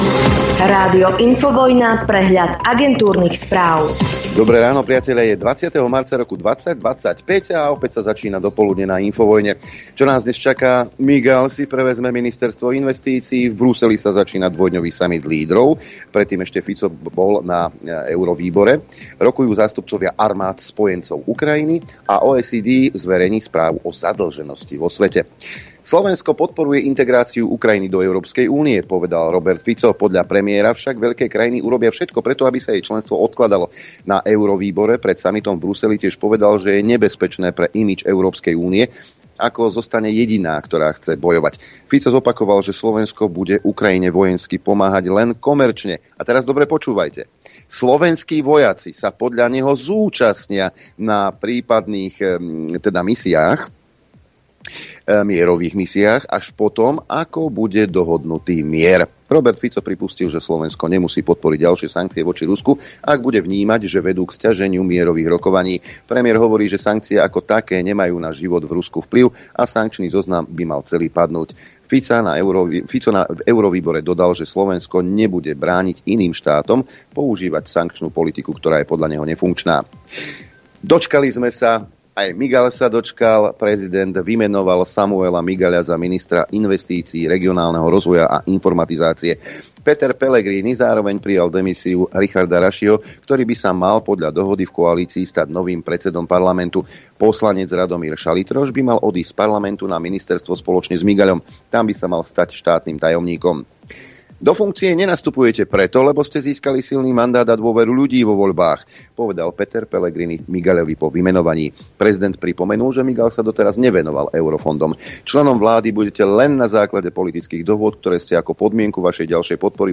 repeat continue pause play stop mute max volume Živé vysielanie 1.